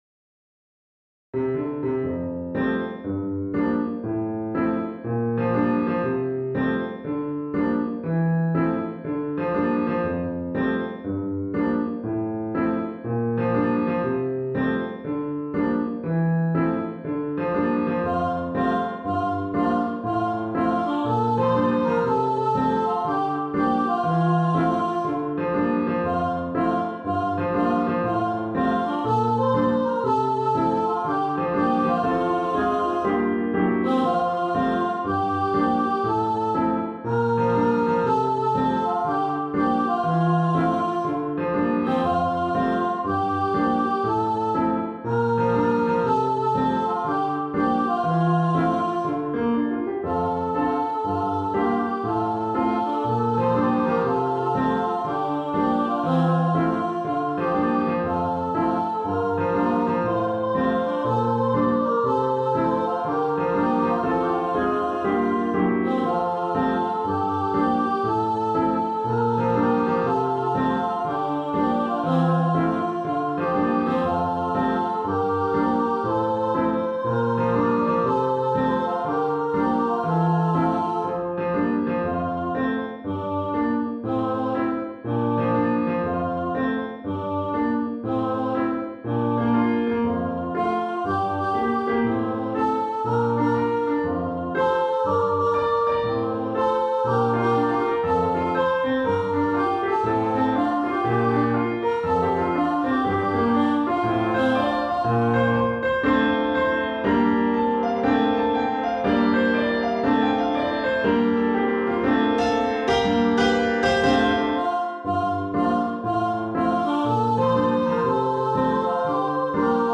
for 2-part choir and piano
MIDI demo